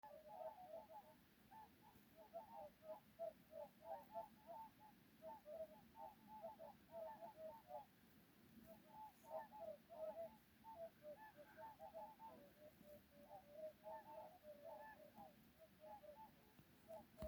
Ziemeļu gulbis, Cygnus cygnus
Administratīvā teritorijaVentspils novads
Skaits55